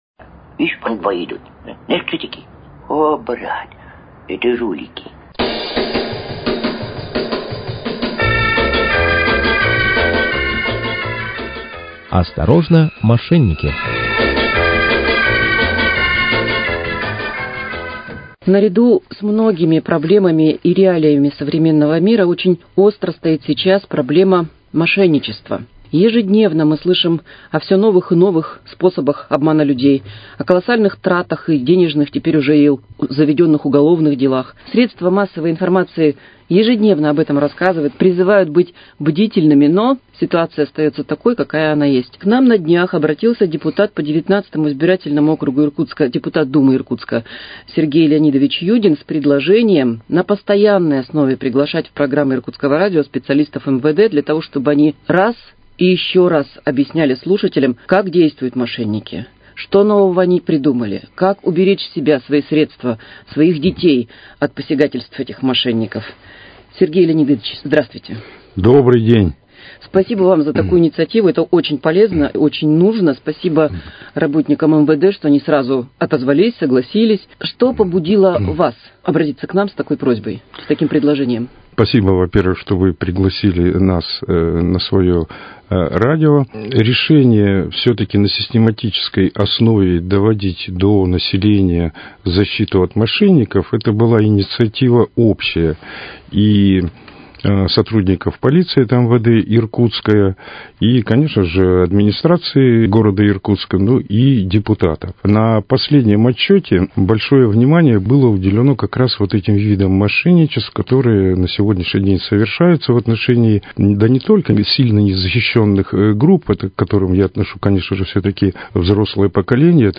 Темой беседы стала профилактика подростковой преступности в контексте массовой вербовки молодых людей в качестве исполнителей для мошеннических и диверсионных схем.